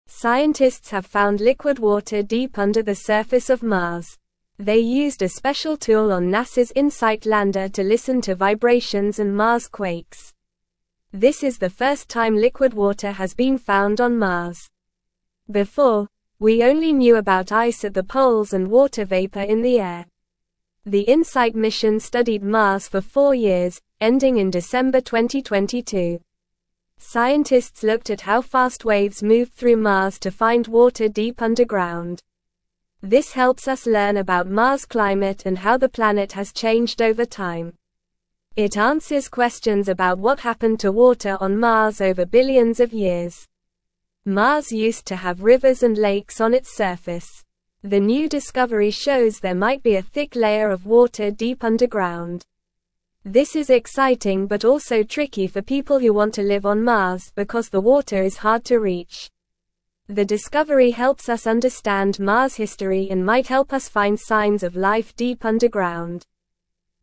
Normal
English-Newsroom-Lower-Intermediate-NORMAL-Reading-Water-Found-on-Mars-Deep-Underground-Excites-Scientists.mp3